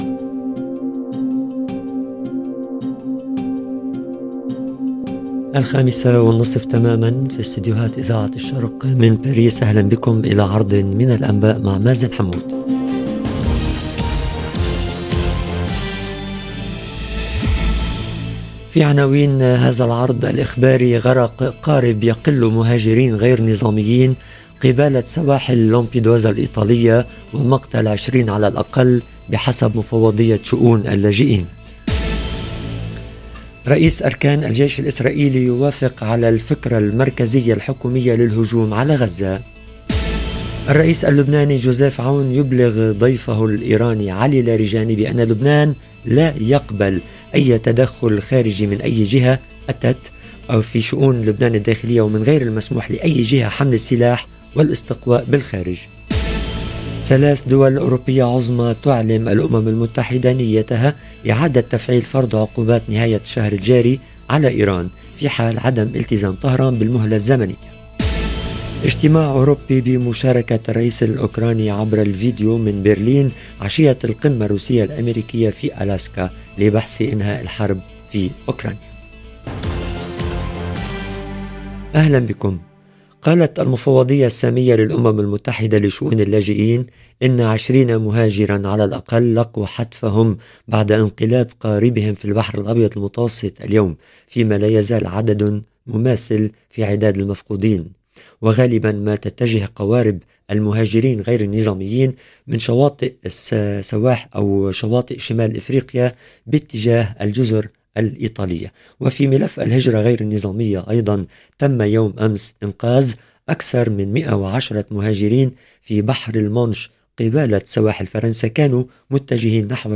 نشرة أخبار المساء: غرق قارب يقل مهاجرين غير نظاميين قبالة سواحل لامبيدوزا الايطالية ومقتل ٢٠ على الاقل بحسب مفوضية شؤون اللاجئين - Radio ORIENT، إذاعة الشرق من باريس